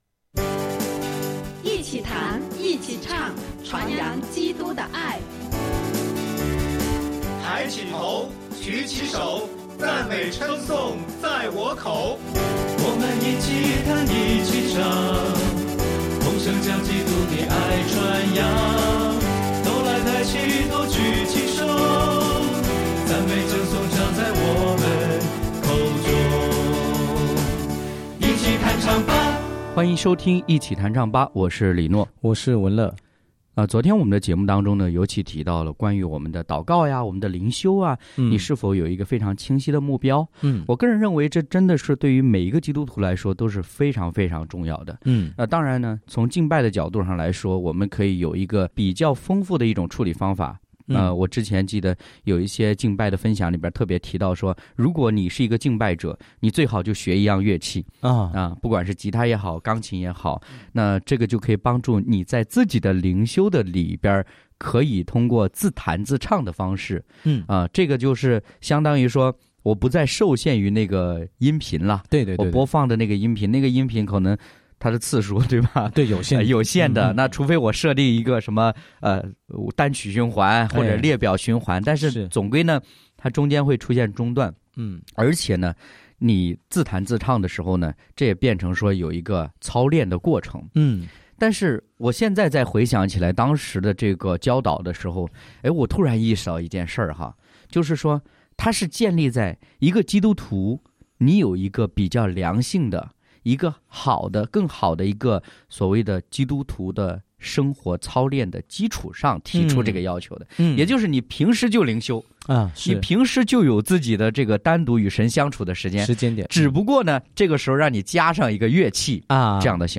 敬拜分享：凡事顺服，讨主喜悦；诗歌：《你的同在》、《用我一生》